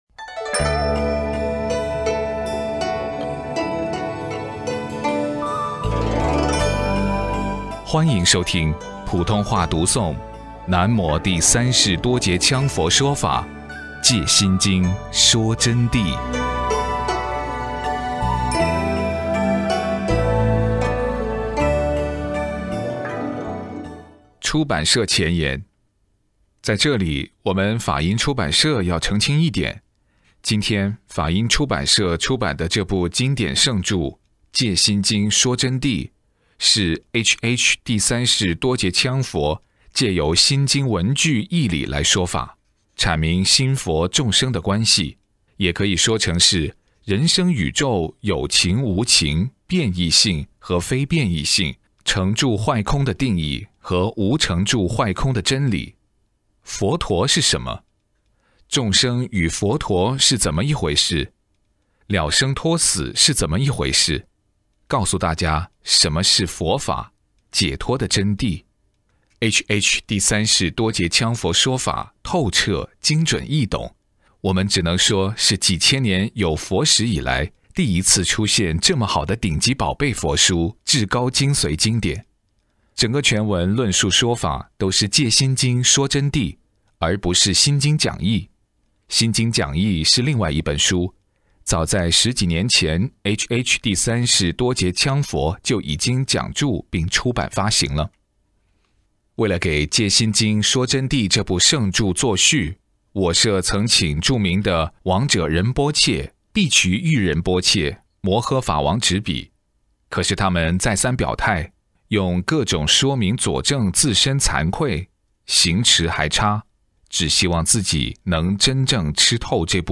【音頻】南無第三世多杰羌佛《藉心經說真諦》普通話恭誦 -《藉心經說真諦》-出版社前言P5-7-普通話恭誦001 – 華藏學佛苑